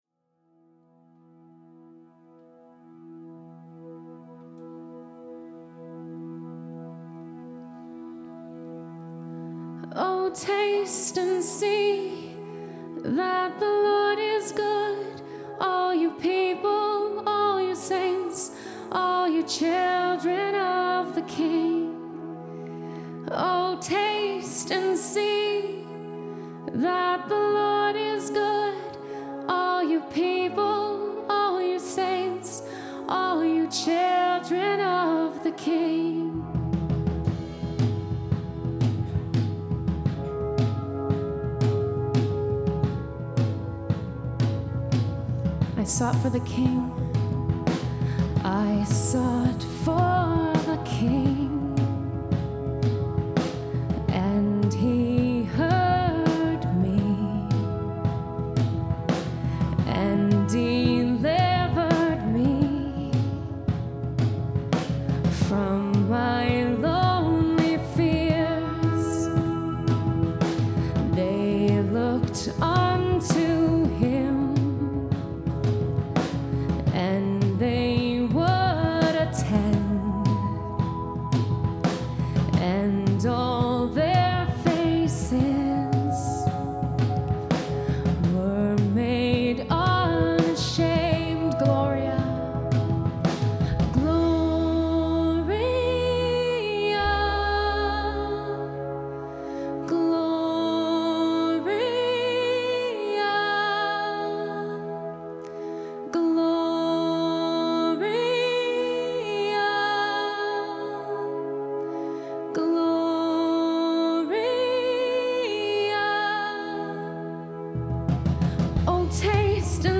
Wayfaring Stranger
"Gloria" as played by Wayfaring Stranger at terra Nova on 3.28.10.